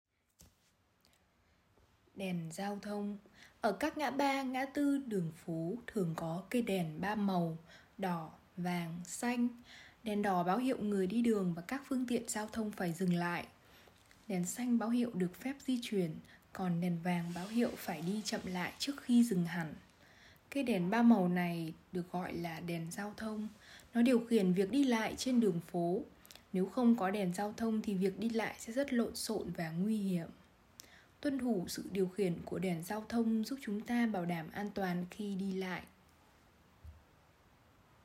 Sách nói | ĐÈN GIAO THÔNG - TIẾNG VIỆT 1